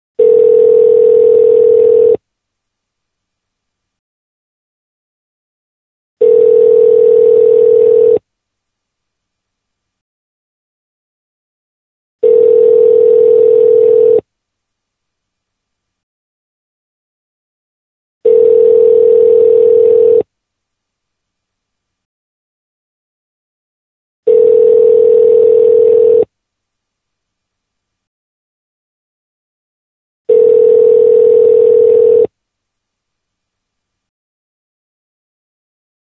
north american ring tone – type b (softer)
north-american-ring-tone-type-b-softer.mp3